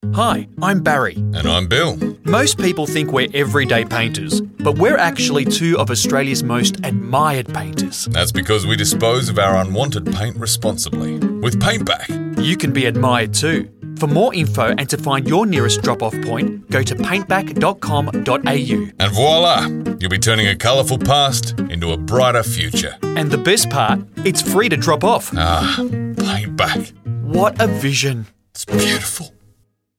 Paintback Advert on KIIS Radio | Paintback